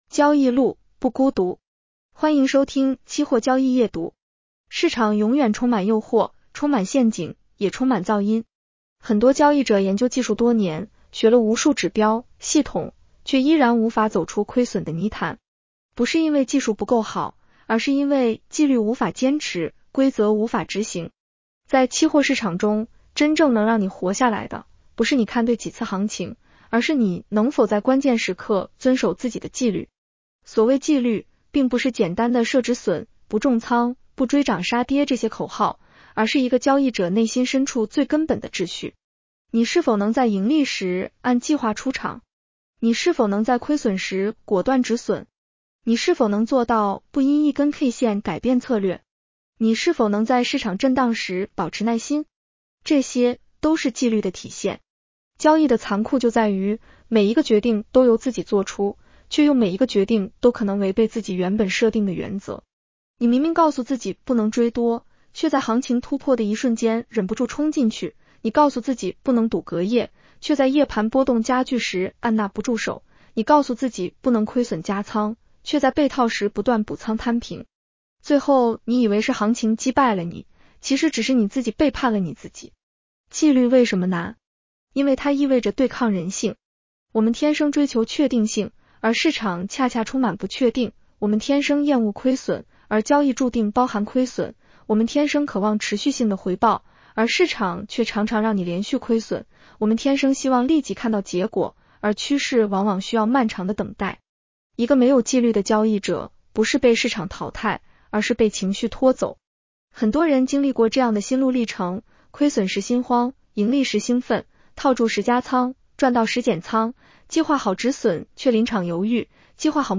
女声普通话版 下载mp3
（AI生成）